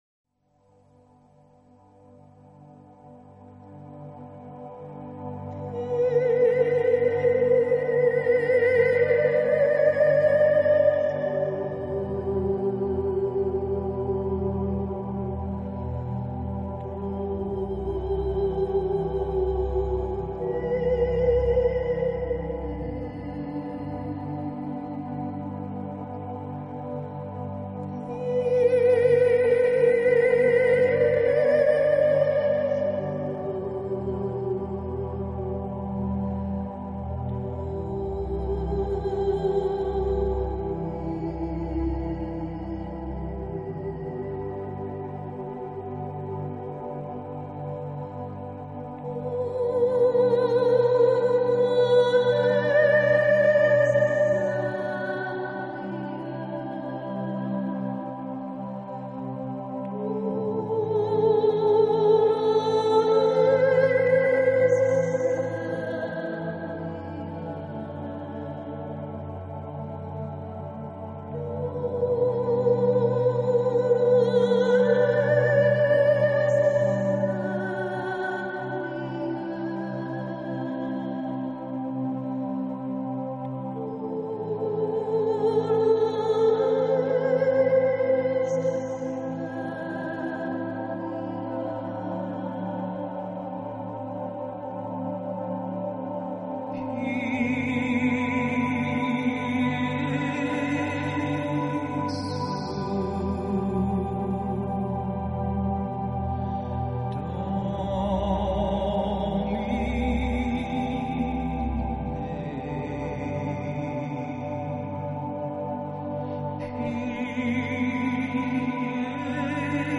或许是旋律过于舒缓